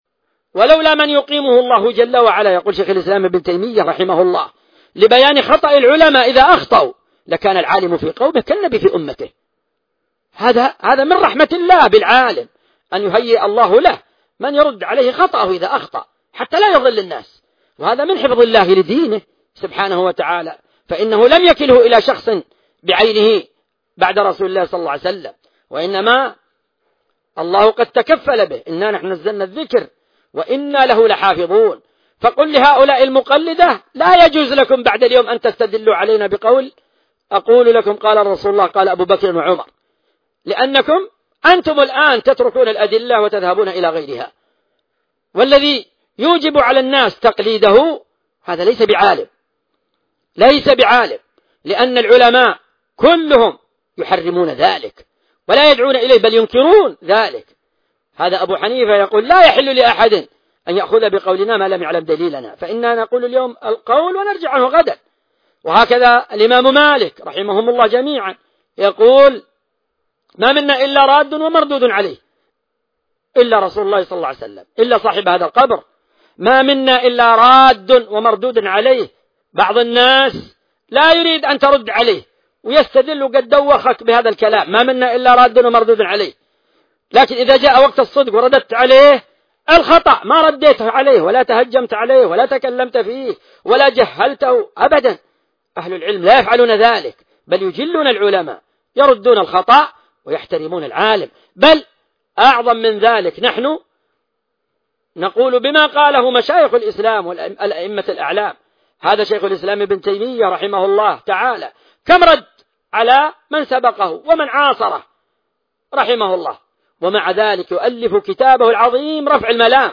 محاضرة آن لمحمد بن هادي أن يخرج عن صماته 2 الشيخ محمد بن هادي المدخلي